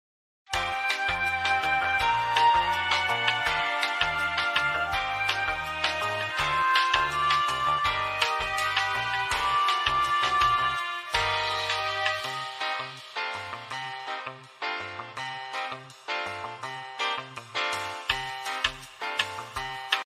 elevator - Botón de Efecto Sonoro